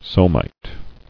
[so·mite]